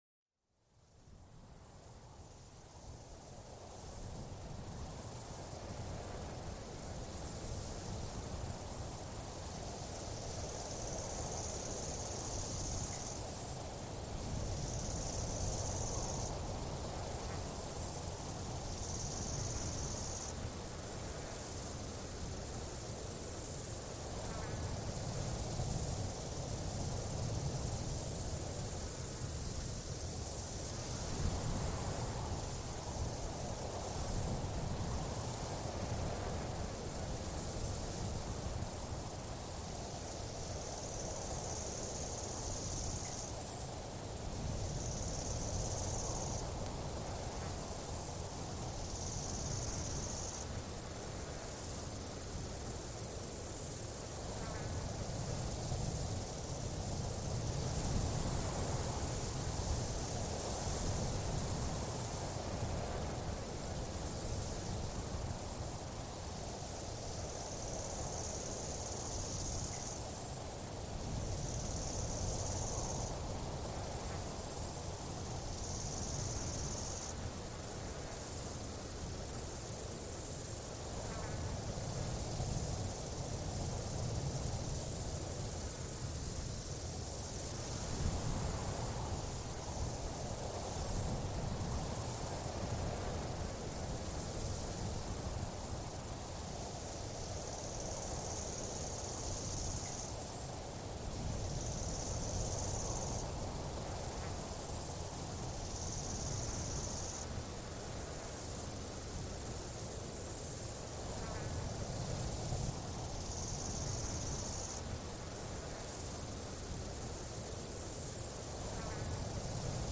Écoutez le doux murmure du vent traversant la campagne paisible. Ce son apaisant est idéal pour se détendre après une longue journée.